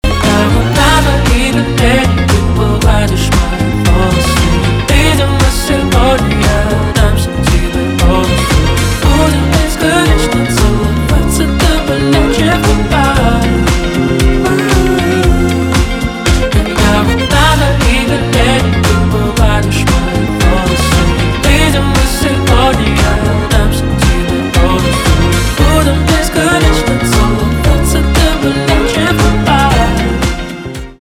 rnb
чувственные , романтические , битовые , кайфовые